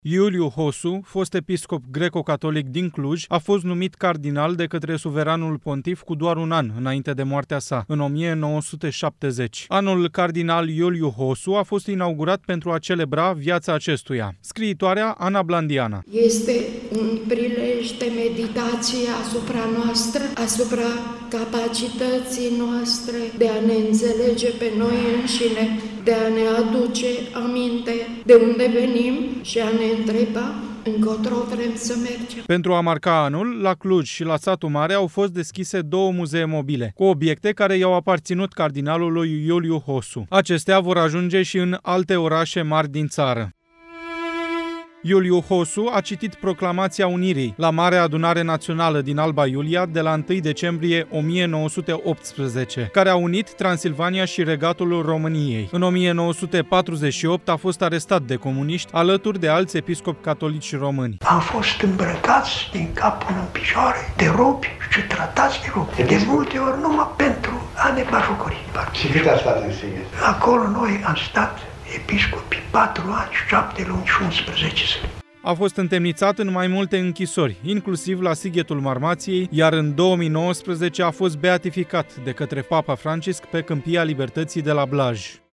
Ceremonie omagială la Academia Română: a fost inaugurat Anul Național Cardinal Iuliu Hossu.
Proclamația Unirii la Marea Adunare Națională din Alba Iulia de la 1 decembrie 1918, citită de Iuliu Hossu